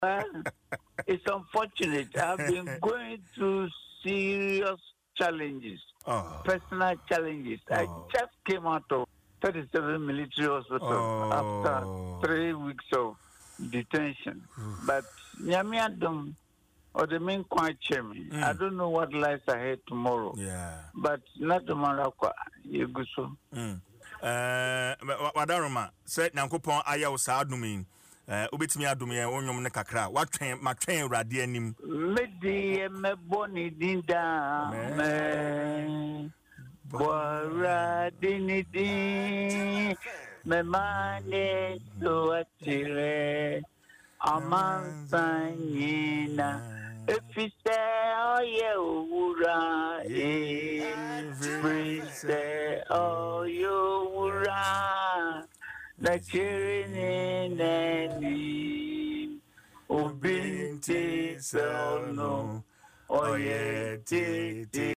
Speaking on Adom FM’s morning show Dwaso Nsem, Captain Effah Dartey revealed that he had been battling health issues and was recently discharged from the 37 Military Hospital after three weeks of medical detention.